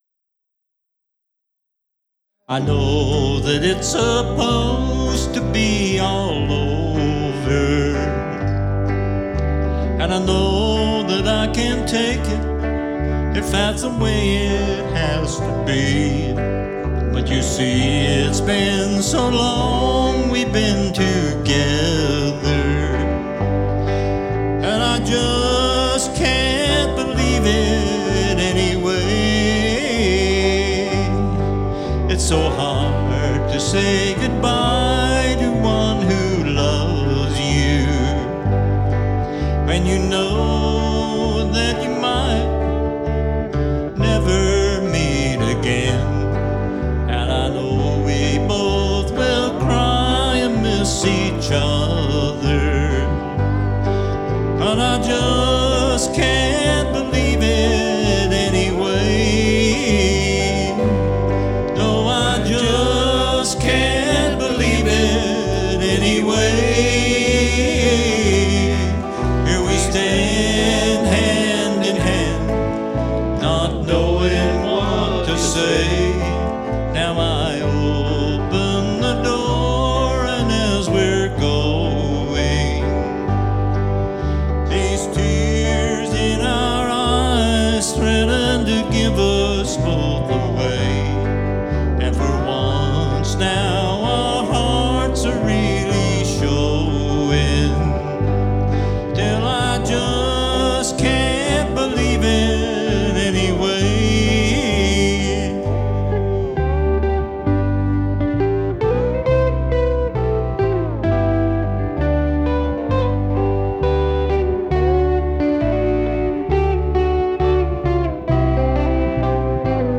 Recording was done on a ZOOM LiveTrak L-12 Mixer/Recorder.
Vocals, Guitar
Lap Steel, Guitar
Bass, Vocals